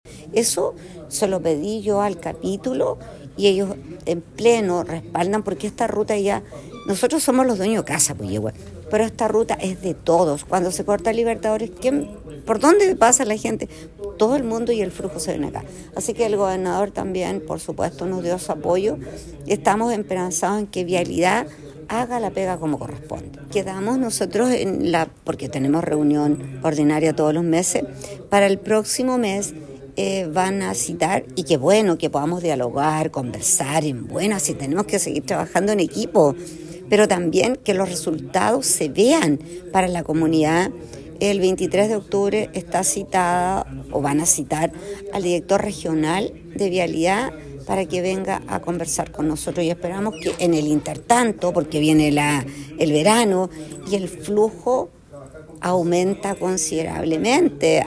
La Alcaldesa de Puyehue, María Jimena Núñez, agradeció el apoyo de los demás jefes comunales, pues esta ruta internacional es la segunda más importante a nivel nacional, concentrando un tránsito importante de vehículos durante todo el año.